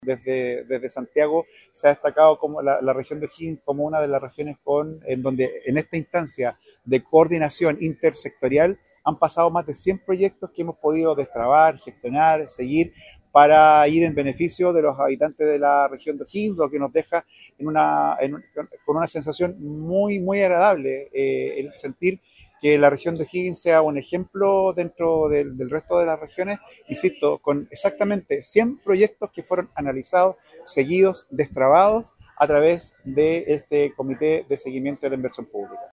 En una sesión realizada este miércoles en el Salón O’Higgins de la Delegación Presidencial Regional (DPR), se llevó a cabo el último Comité de Seguimiento a la Inversión (CSI) del periodo 2022-2026.
Escuchemos al Delegado Presidencial Fabio López: